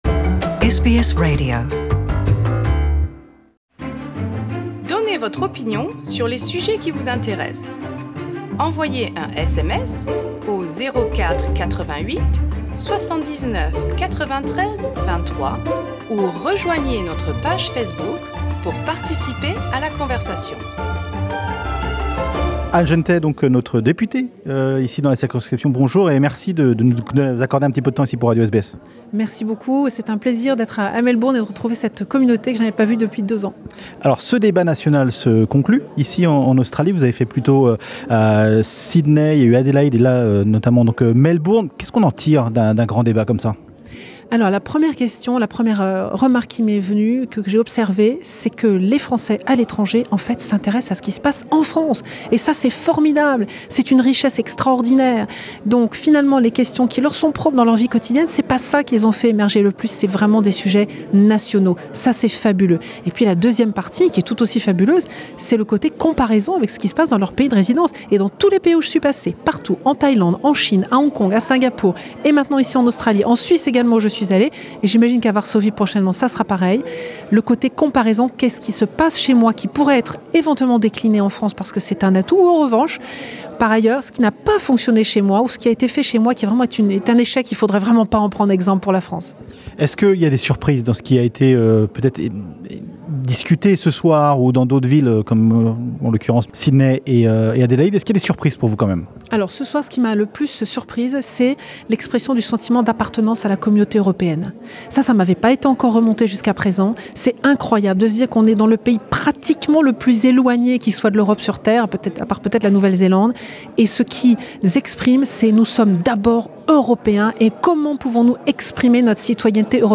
Rencontre et bilan du grand débat francais .. en Australie avec Anne Genetet, la députée des français de l'etranger